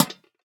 Minecraft Version Minecraft Version snapshot Latest Release | Latest Snapshot snapshot / assets / minecraft / sounds / block / lantern / place1.ogg Compare With Compare With Latest Release | Latest Snapshot